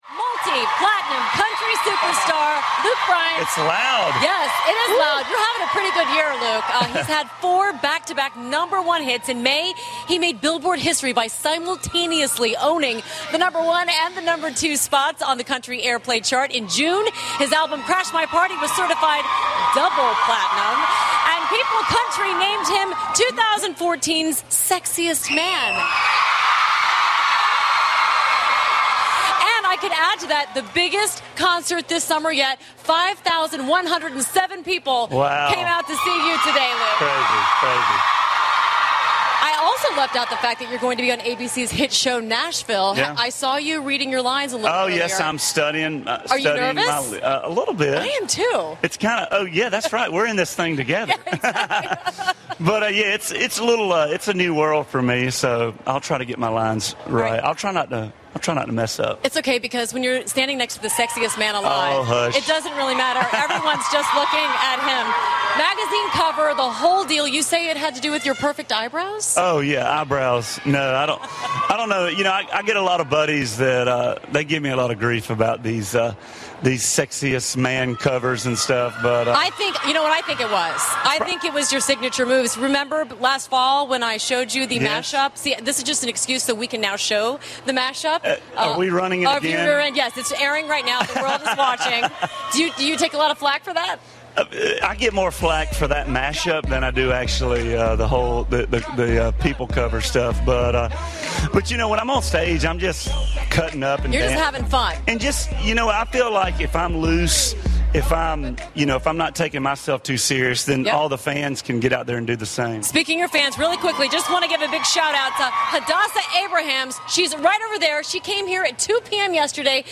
访谈录 2014-08-15&08-17 乡村歌手卢克·布莱恩答记者问 听力文件下载—在线英语听力室